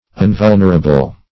Unvulnerable \Un*vul"ner*a*ble\, a.